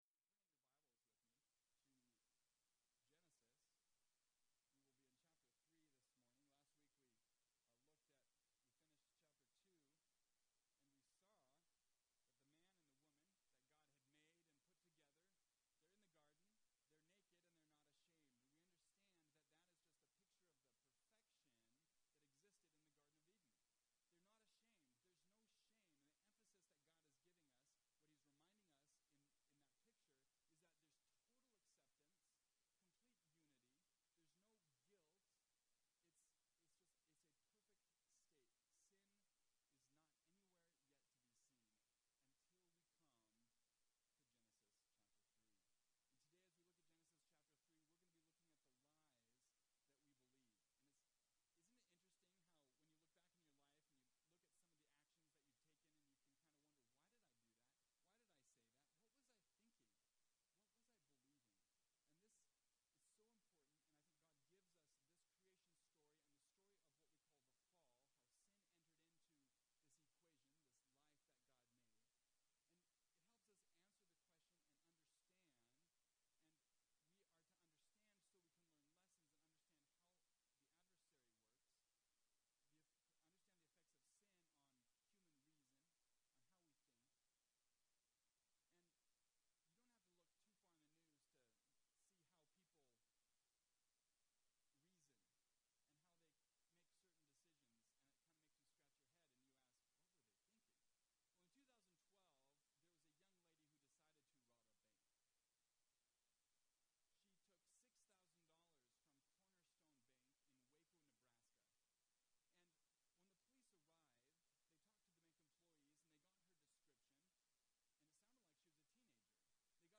The Lies We Believe (Genesis 3) – Mountain View Baptist Church